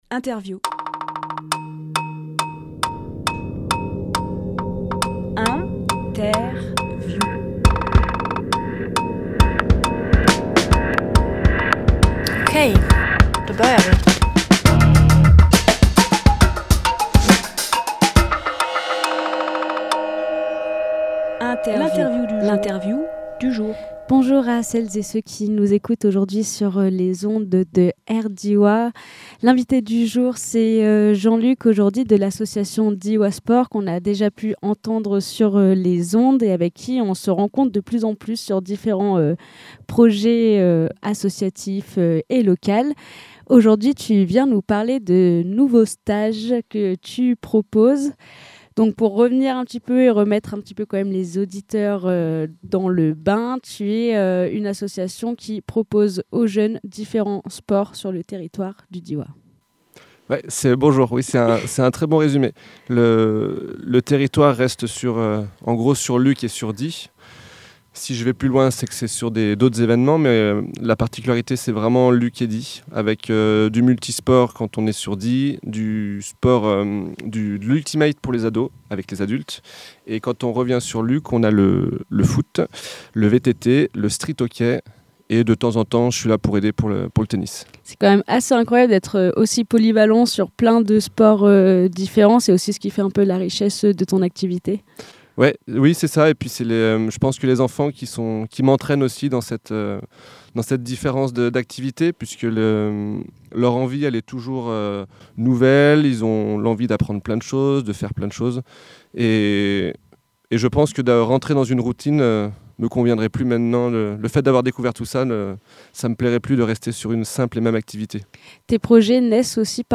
Emission - Interview Diois Sport – Les nouveaux rendez-vous!